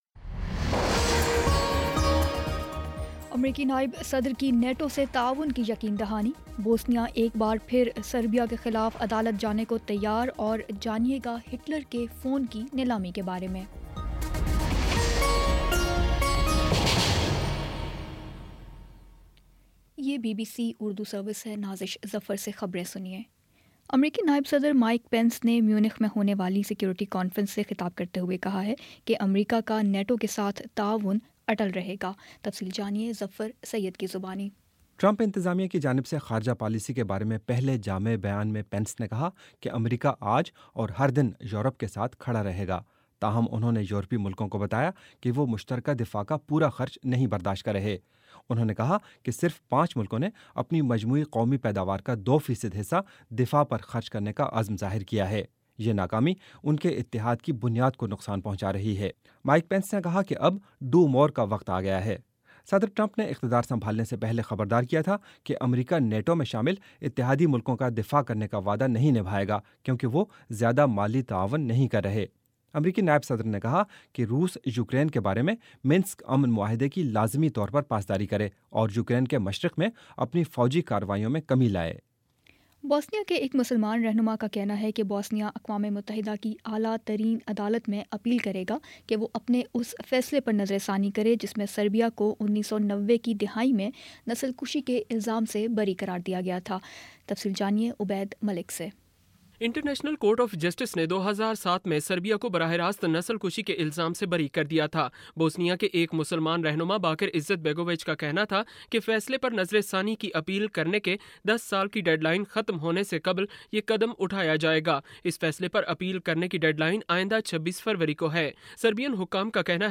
فروری 18 : شام پانچ بجے کا نیوز بُلیٹن